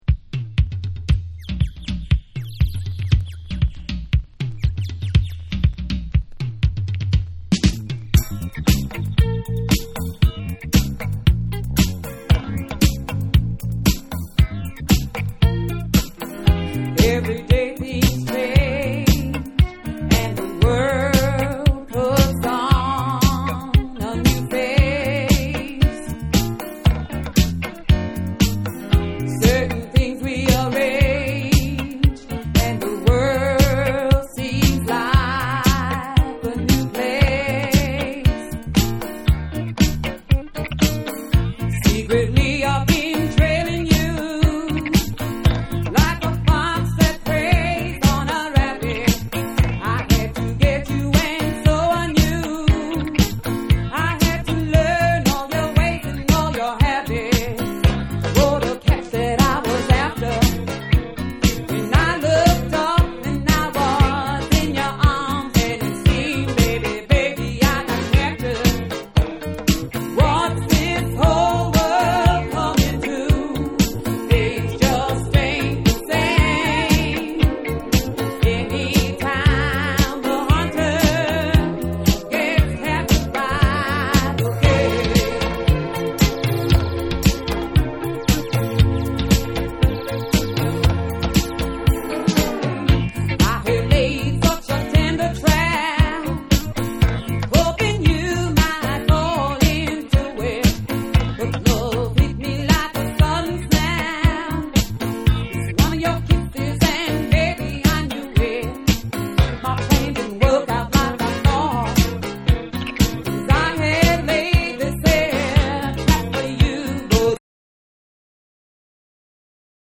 ロック〜ディスコ〜ダブなど様々な要素が一体となり
アヴァンギャルドなダンス・ナンバーを全4曲収録。
NEW WAVE & ROCK